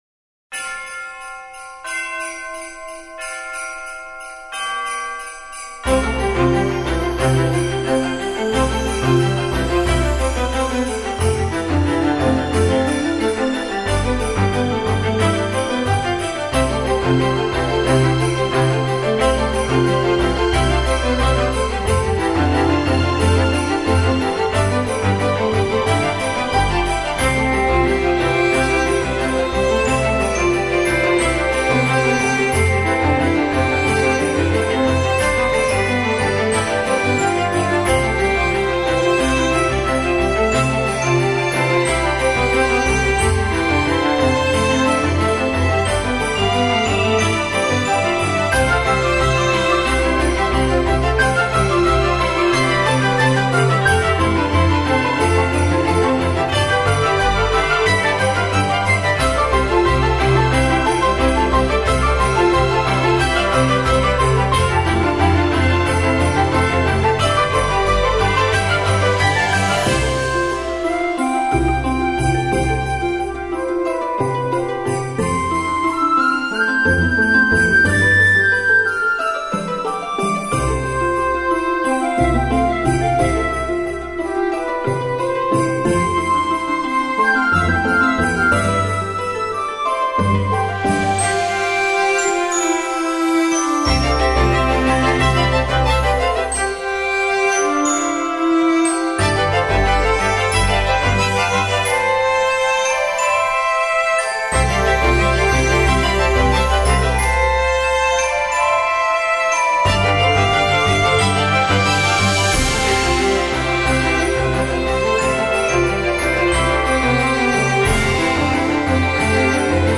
Новогодняя мелодия с курантами